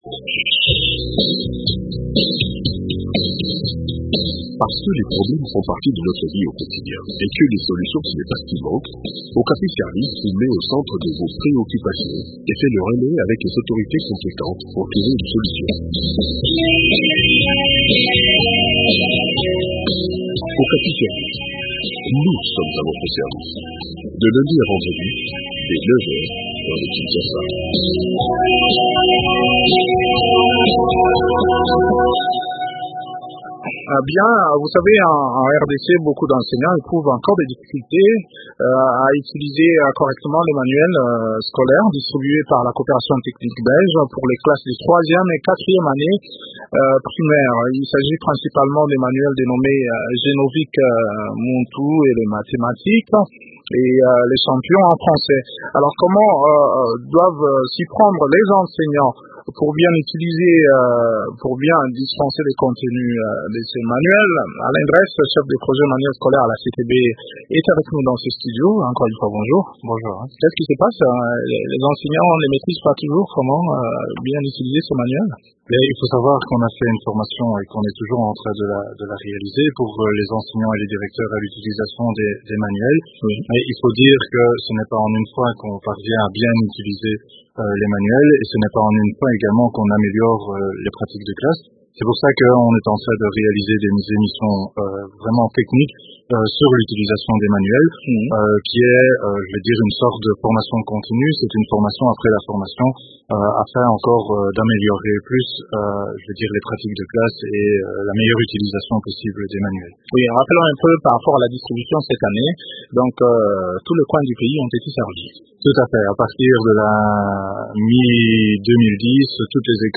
Des précisions sur le sujet dans cet entretien